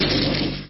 123-Thunder01.mp3